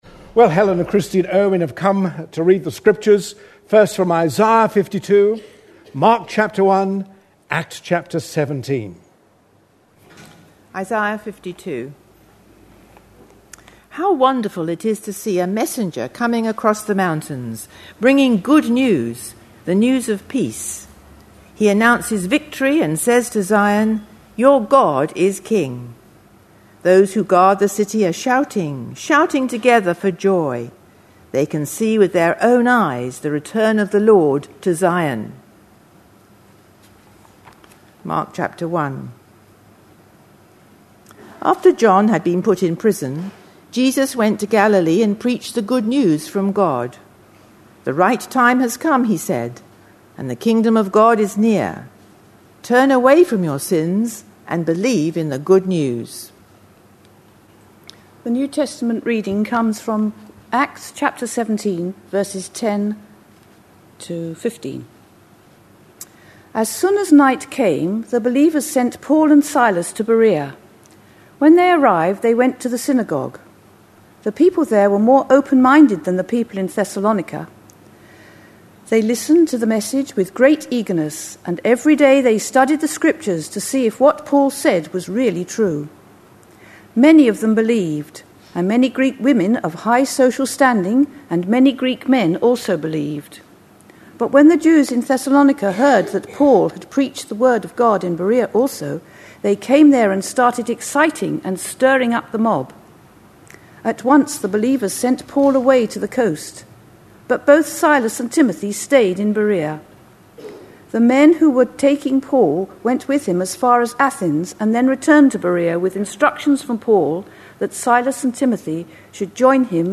A sermon preached on 11th July, 2010, as part of our Acts series.